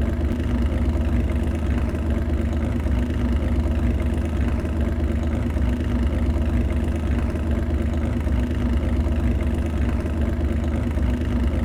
Index of /server/sound/vehicles/lwcars/porsche_911_rsr
idle.wav